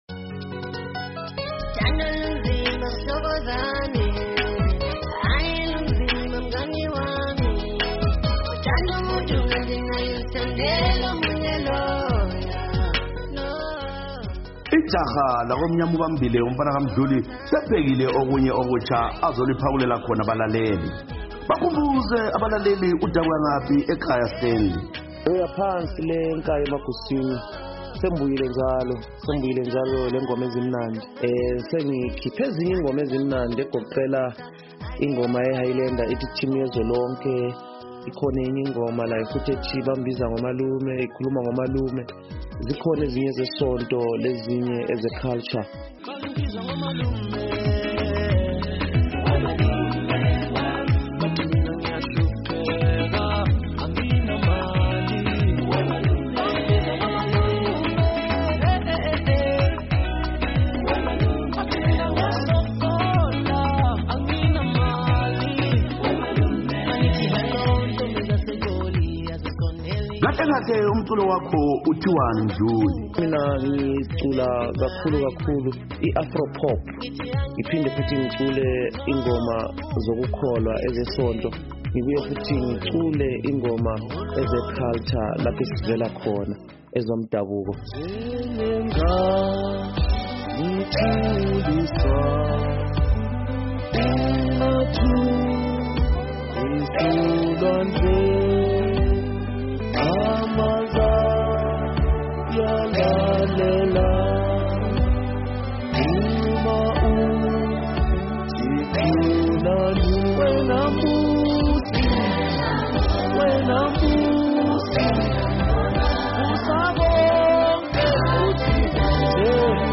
Woza Friday: Sixoxa Lomculi